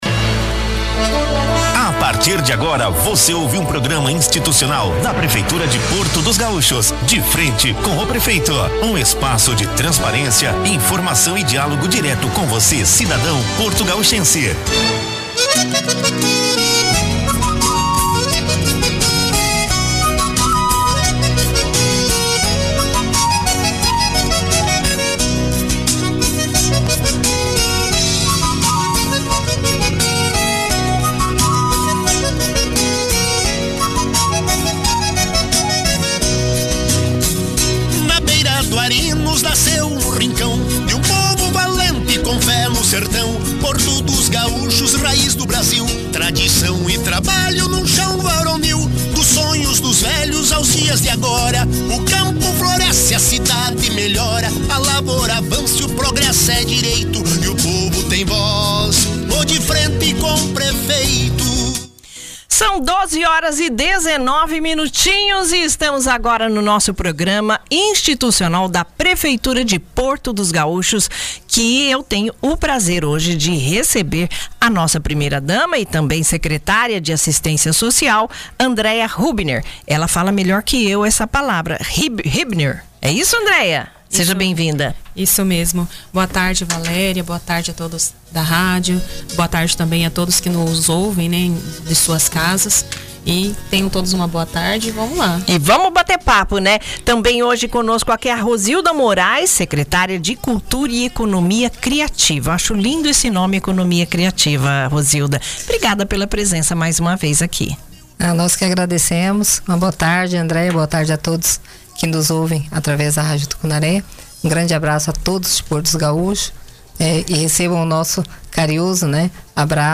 A população de Porto dos Gaúchos foi atualizada sobre importantes avanços administrativos durante mais uma edição do programa institucional “De Frente com o Prefeito”, transmitido pela Rádio Tucunaré no dia 10 de abril, às 12h19.
Participaram da entrevista a primeira-dama e secretária de Assistência Social, Andreia Hübner, e a secretária de Cultura e Economia Criativa, Rosilda Moraes, que apresentaram um panorama das ações em andamento e conquistas recentes da gestão municipal.